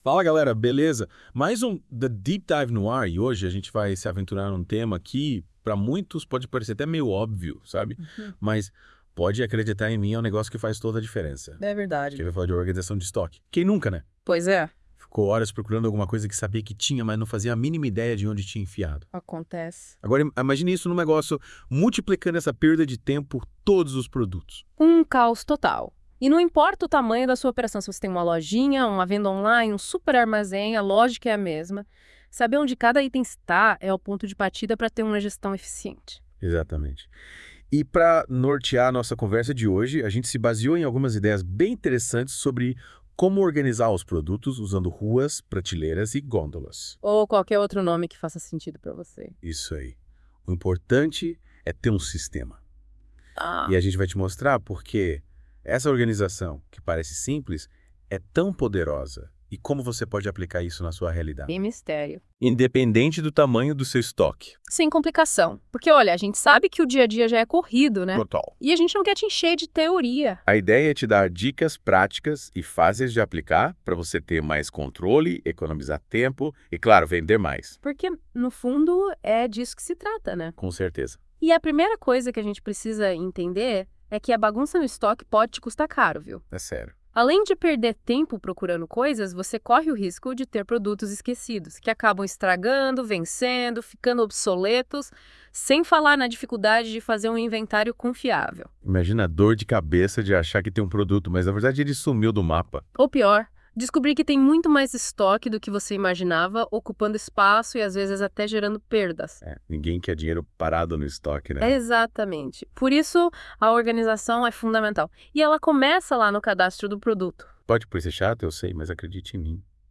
Audio Aula